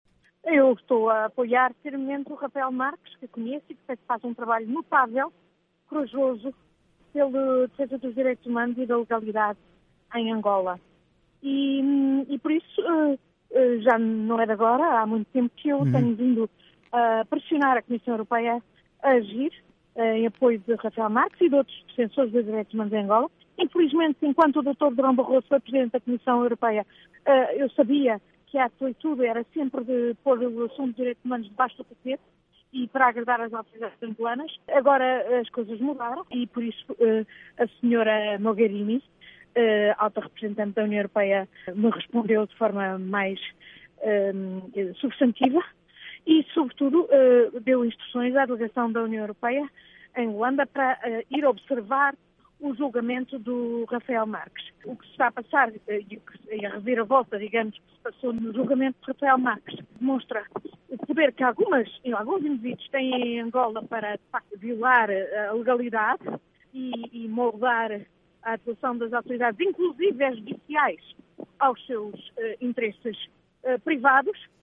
Ana Gomes revela o seu posicionamento em relação ao caso e critica o que chama de poder de alguns em moldar a justiça angolana a favor dos seus interesses privados.